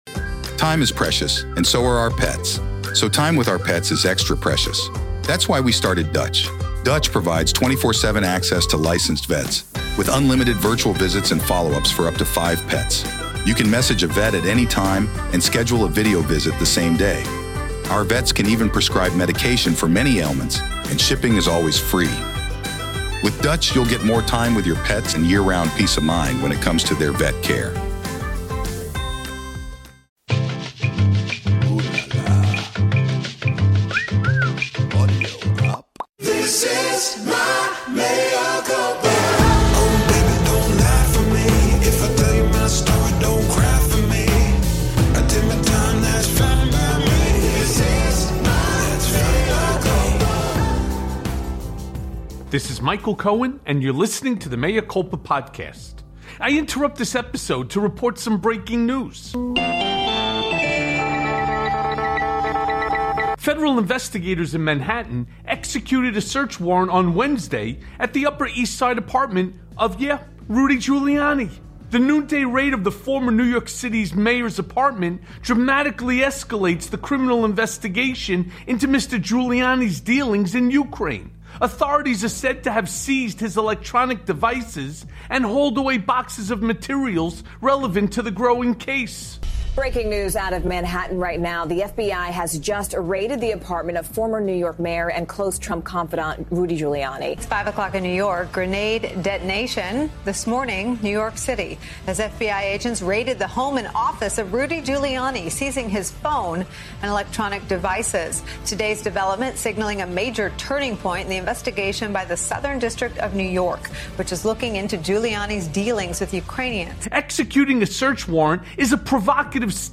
Inside the FBI’s Rudy Giuliani Apt Raid + A Conversation With WaPo’s Greg Sargent